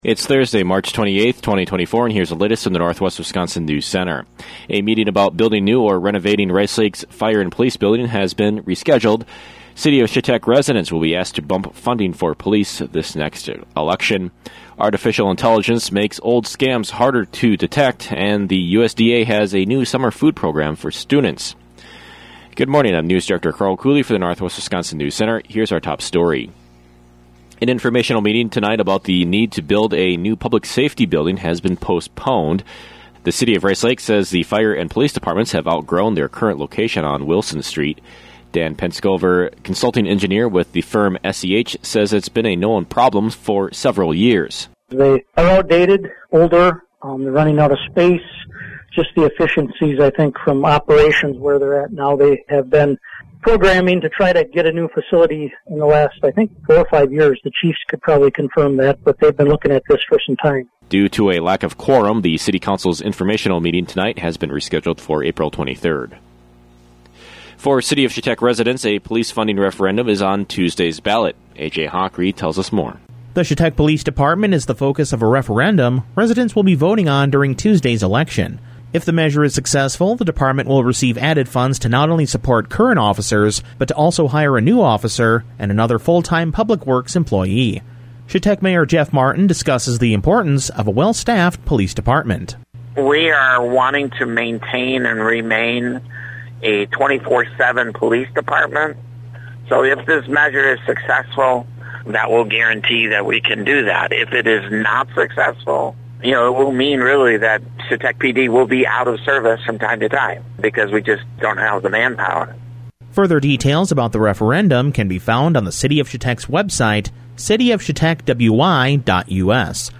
AM NEWSCAST Thursday, March 28, 2024 | Northwest Builders, Inc.